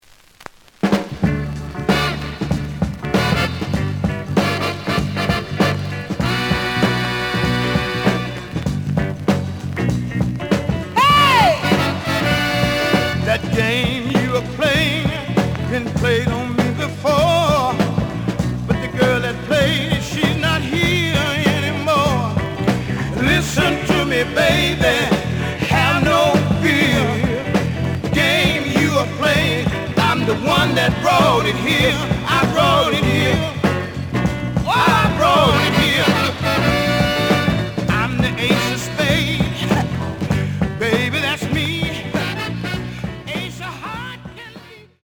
The audio sample is recorded from the actual item.
●Genre: Soul, 70's Soul
Some periodic noise on first half of A side.